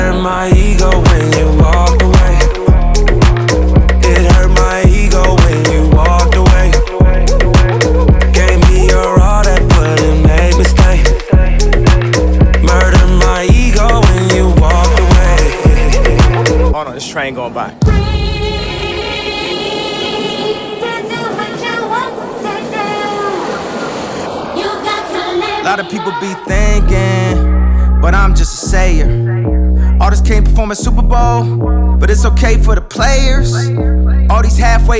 Жанр: Хип-Хоп / Рэп / R&B / Соул